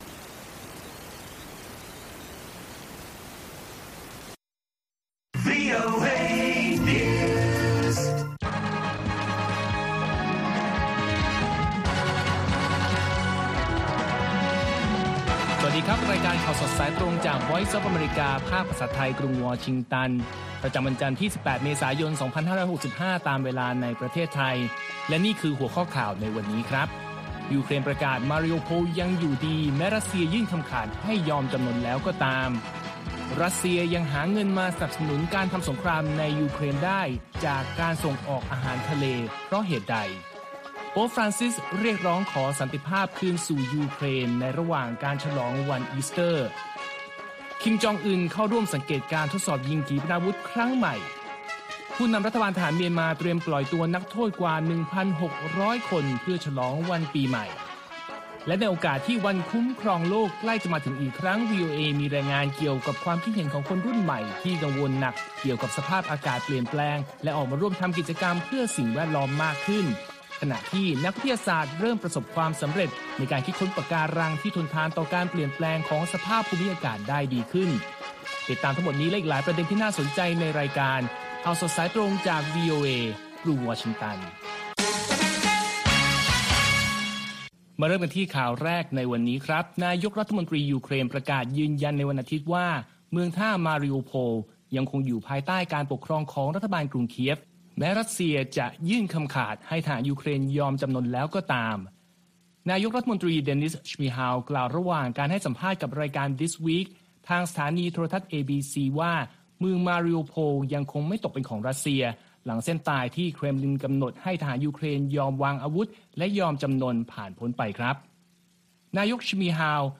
ข่าวสดสายตรงจากวีโอเอ ภาคภาษาไทย 8:30–9:00 น. ประจำวันจันทร์ที่ 18 เมษายน 2565 ตามเวลาในประเทศไทย